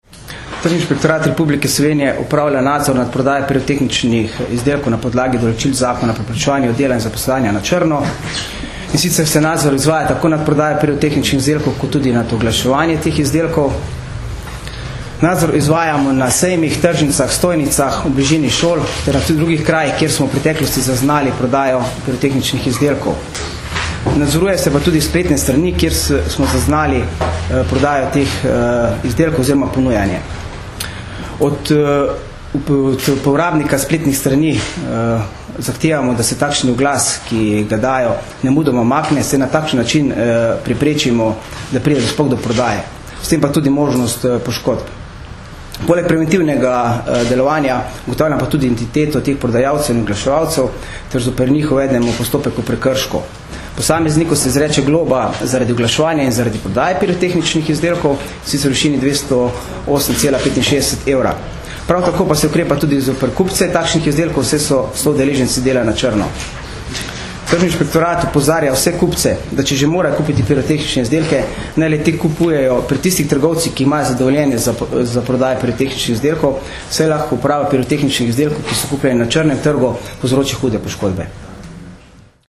Na današnji novinarski konferenci smo zato predstavili naše aktivnosti in prizadevanja, da bi bilo med božično-novoletnimi prazniki čim manj kršitev in telesnih poškodb zaradi neprevidne, nepremišljene in objestne uporabe pirotehničnih izdelkov.